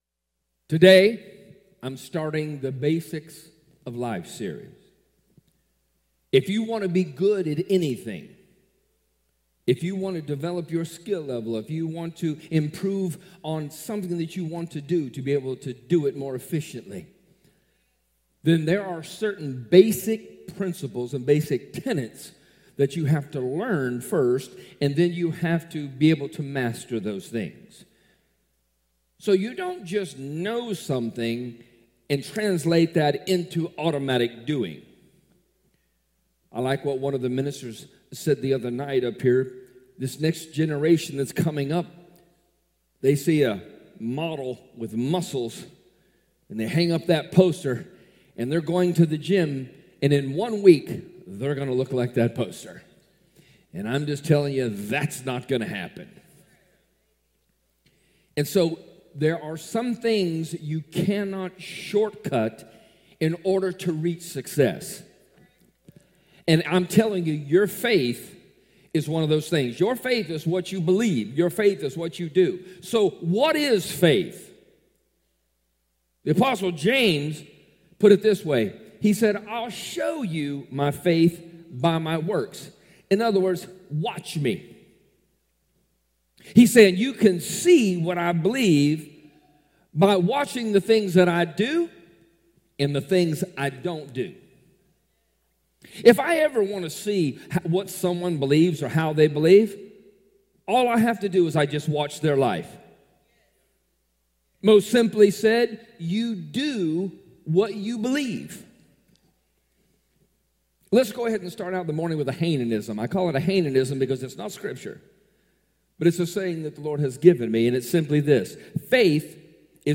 Sunday and Wednesday sermons from Glory To Him Church in Ozark, AL.